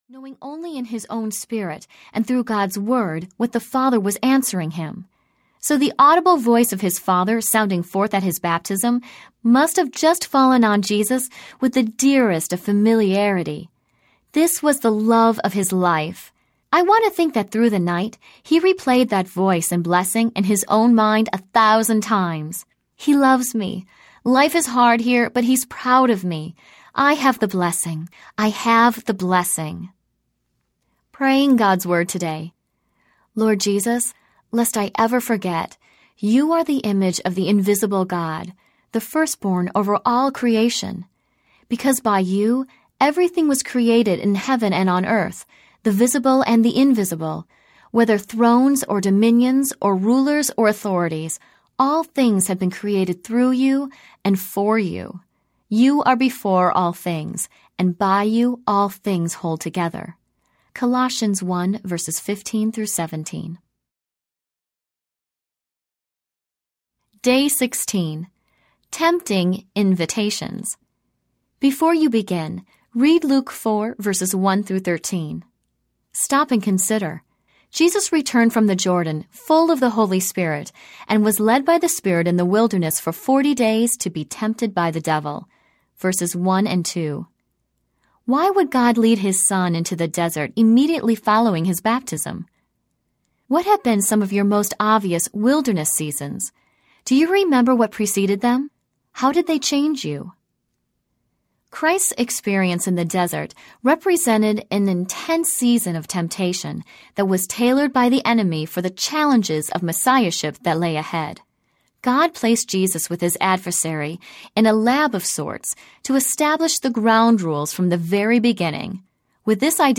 Jesus: 90 Days with the One and Only Audiobook
Narrator
8 Hrs. – Unabridged